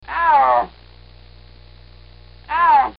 Snow Leopard sound